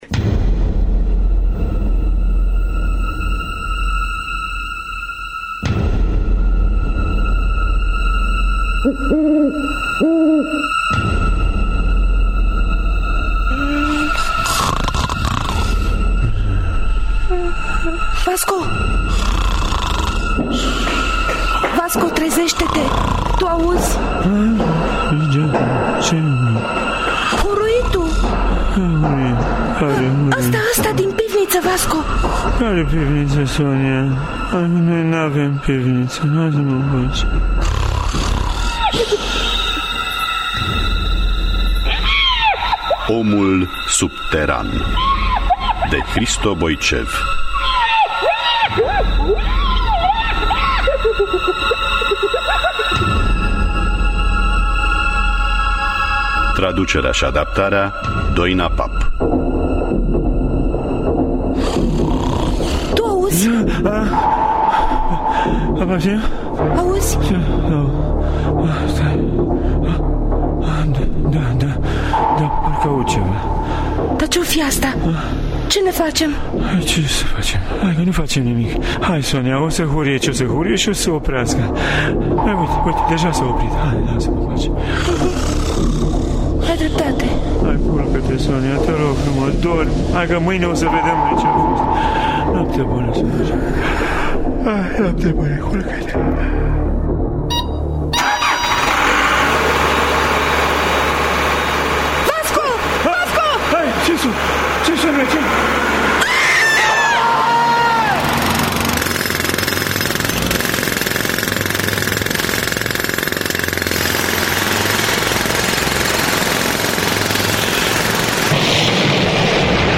– Teatru Radiofonic Online